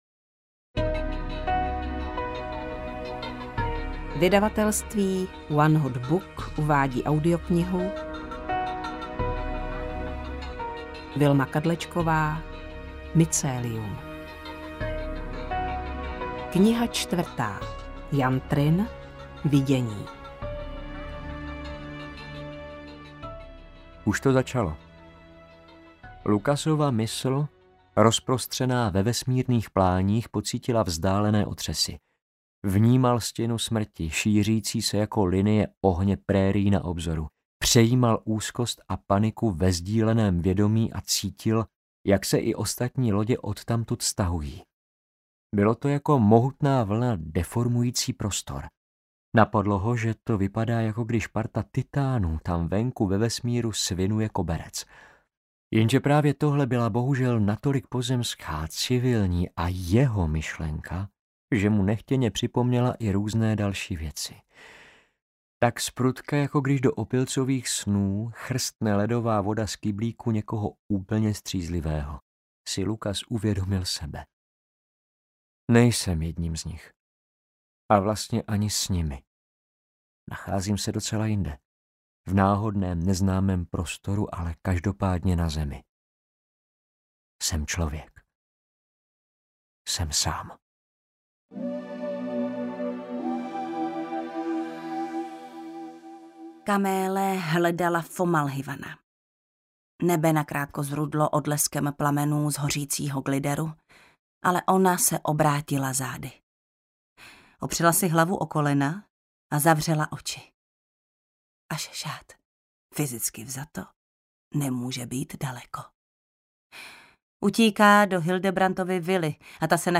Mycelium IV: Vidění audiokniha
Ukázka z knihy